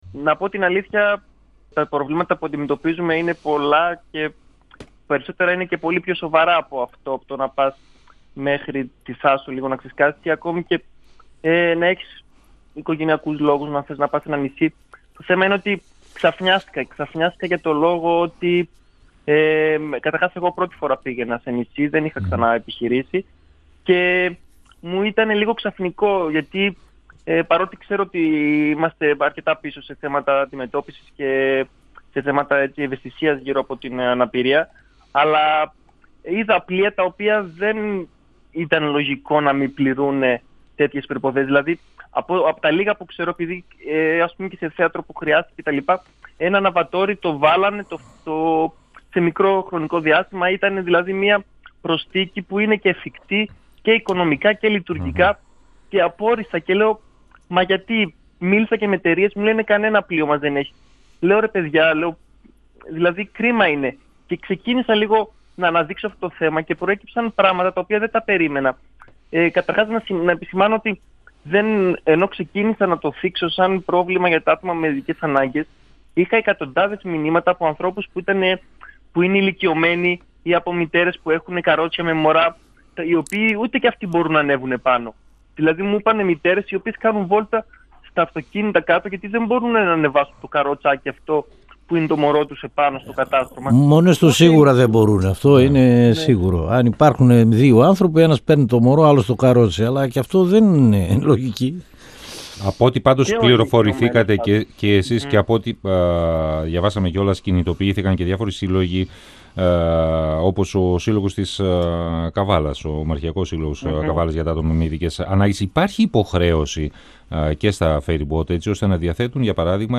μιλώντας στον 102FM του Ραδιοφωνικού Σταθμού Μακεδονίας της ΕΡΤ3.